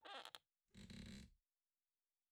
02_孤儿院走廊_跷跷板声音.wav